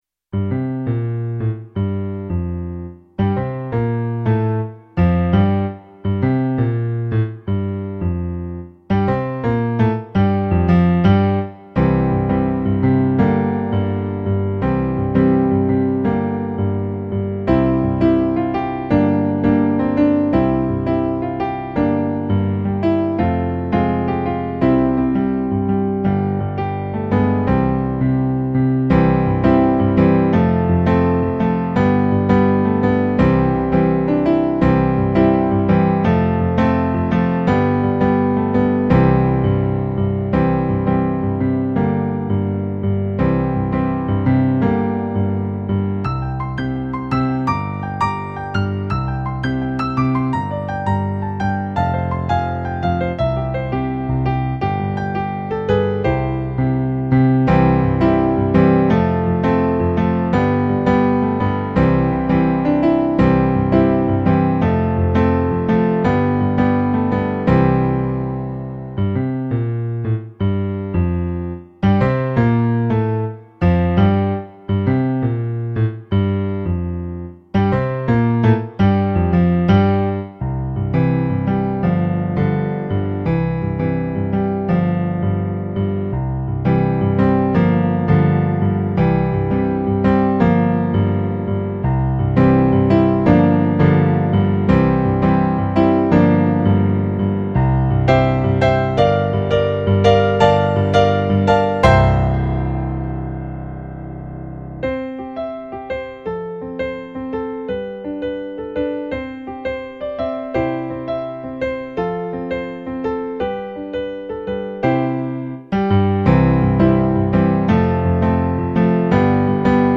eight piano solo arrangements.  31 pages.
Latin remix